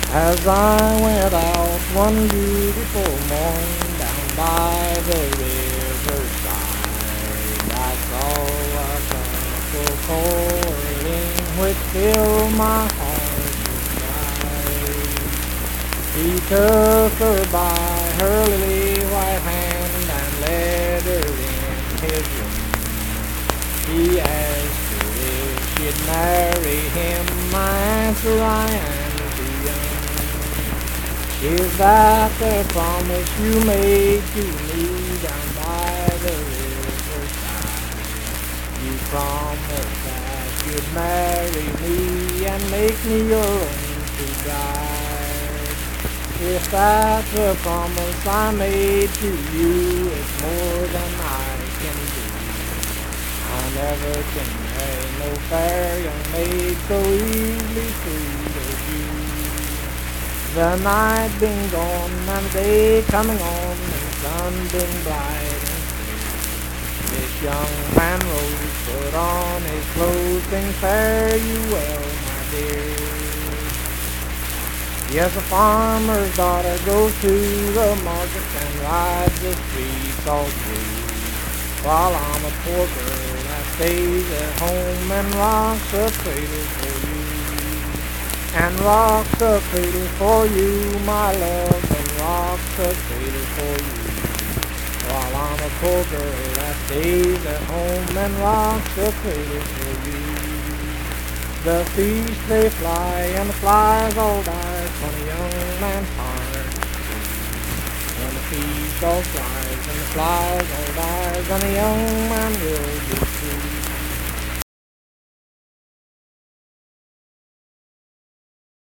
Unaccompanied vocal music
Voice (sung)
Pleasants County (W. Va.), Saint Marys (W. Va.)